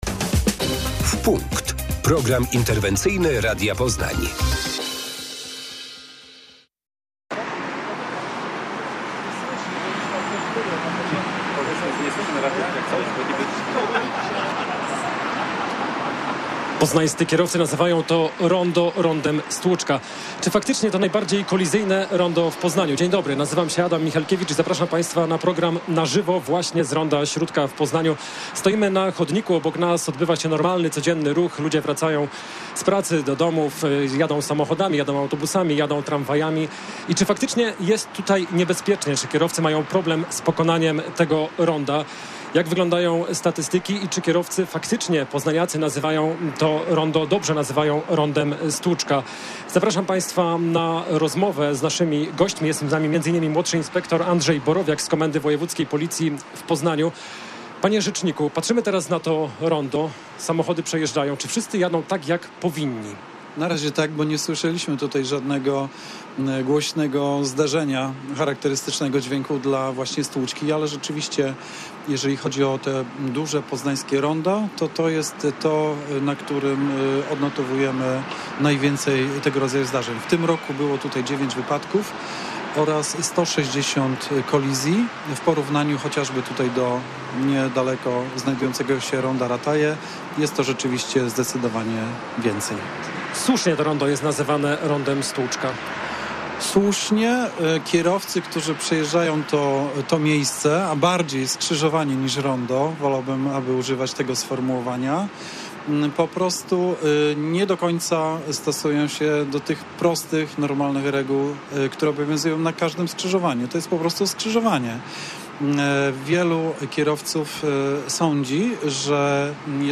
O tym, co można zrobić, by tych zdarzeń było mniej rozmawiali goście wieczornego magazynu "W punkt" w Radiu Poznań.